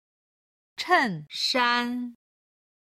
衬衫　(chèn shān)　ワイシャツ